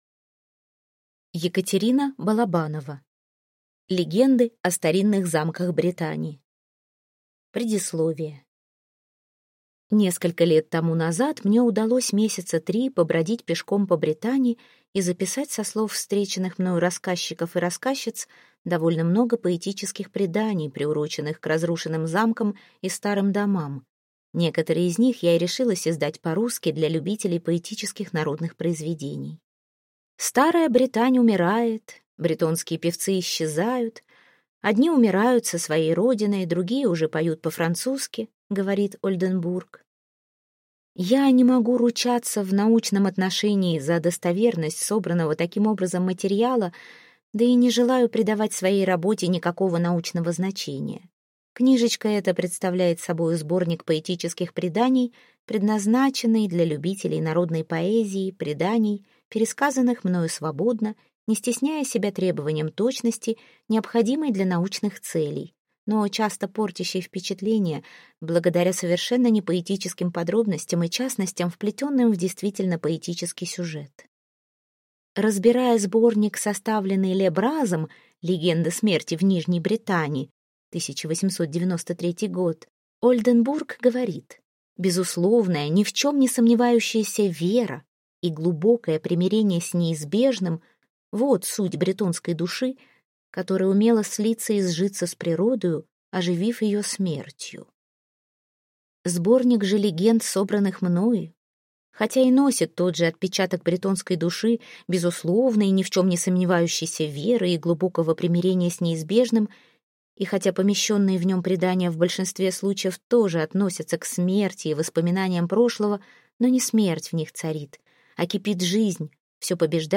Аудиокнига Легенды о старинных замках Бретани | Библиотека аудиокниг